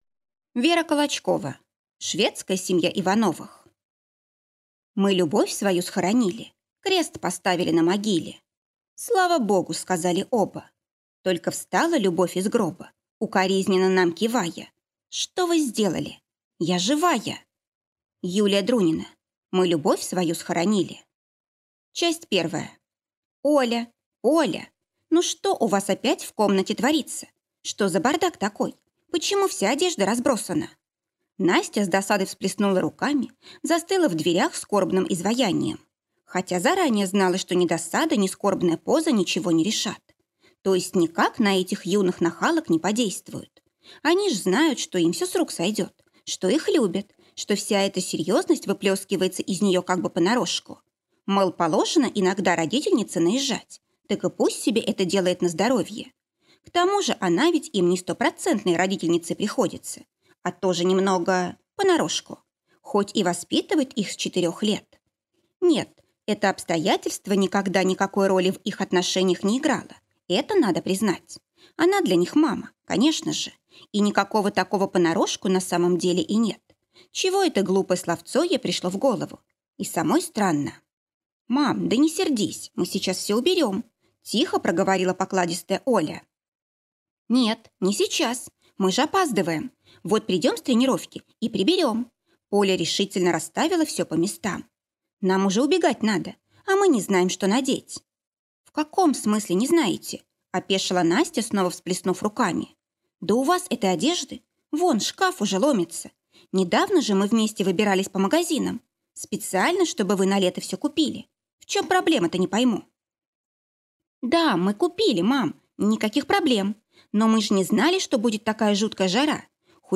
Аудиокнига Шведская семья Ивановых | Библиотека аудиокниг